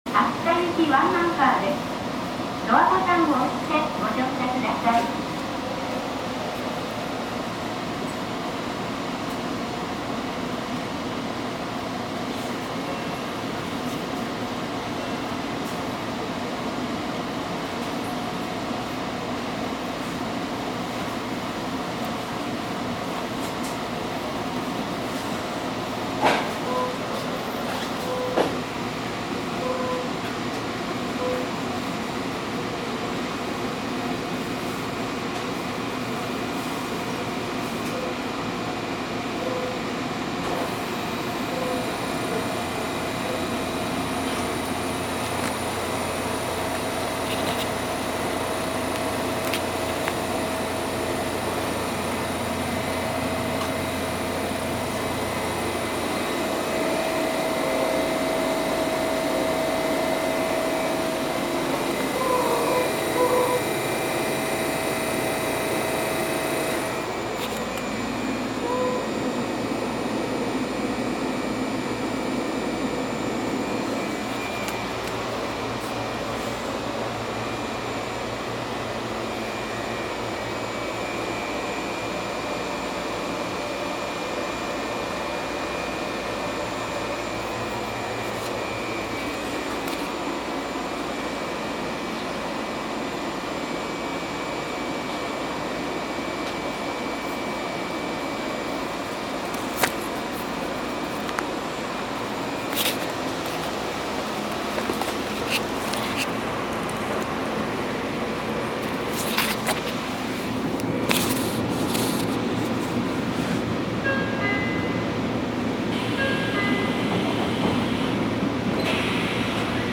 走行音
録音区間：追分～上飯島(お持ち帰り)